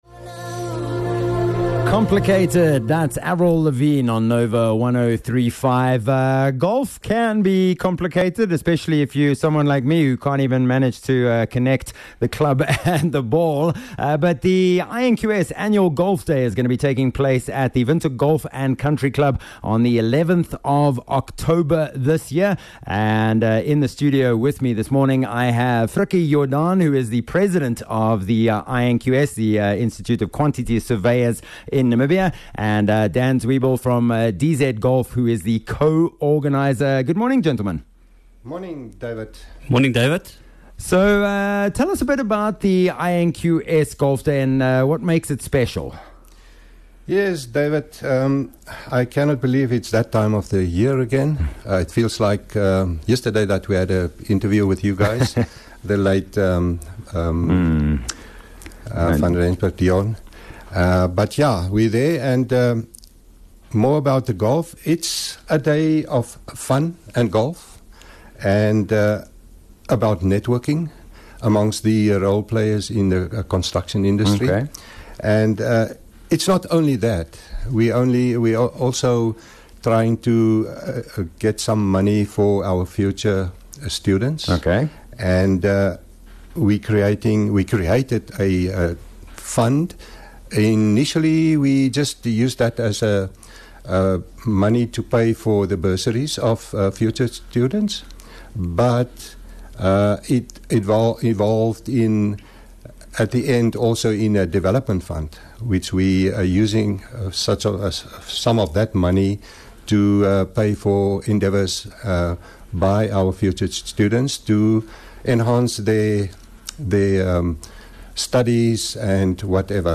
1 Oct INQS Golf Day Interview 1